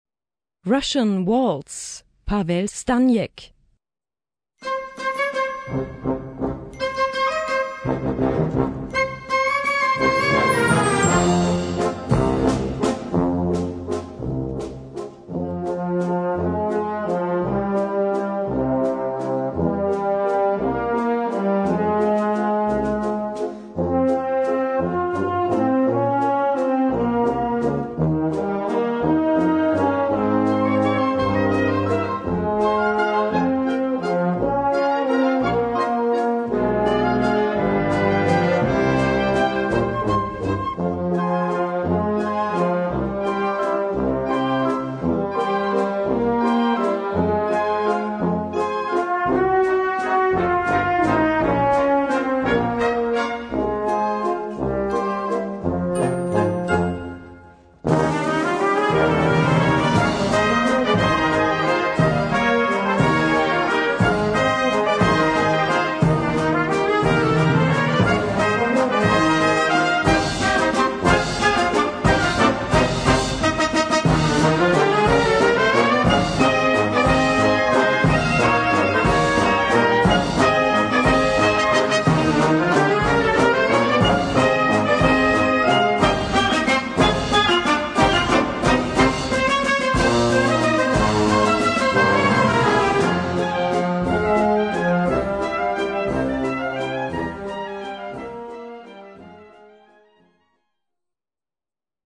Gattung: Walzer
A4 Besetzung: Blasorchester Zu hören auf